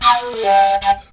FLUTELIN02.wav